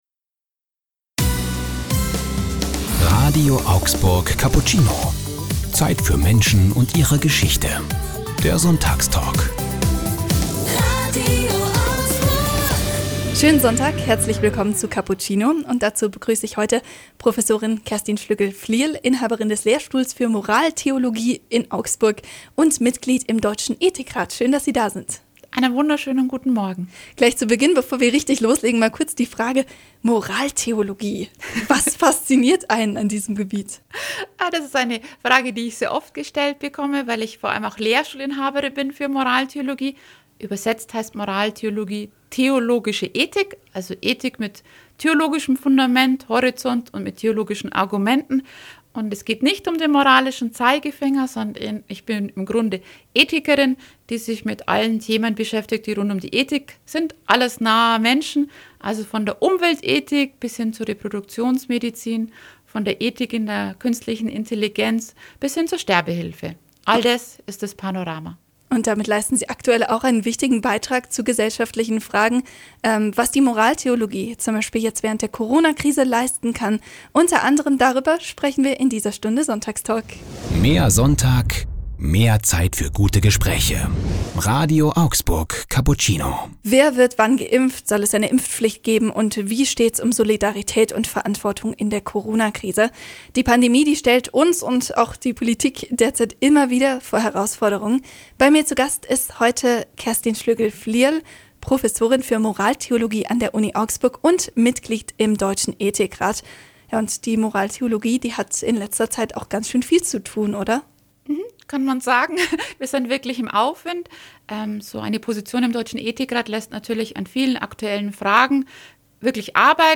Sie haben den Sonntagstalk verpasst?